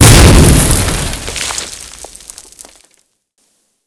GrenExpl01.wav